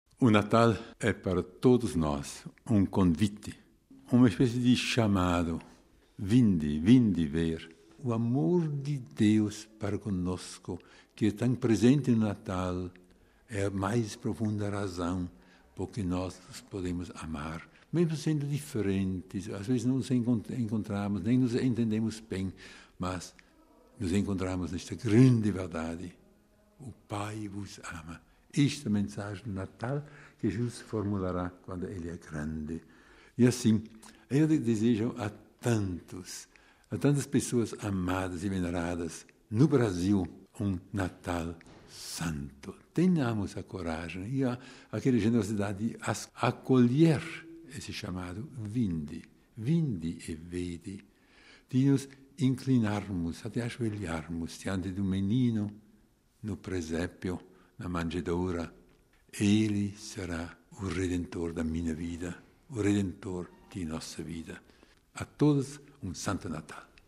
Para os votos de Natal, o Programa Brasileiro contatou o secretário emérito do Pontifício Conselho para a Família, Dom Karl Romer: RealAudio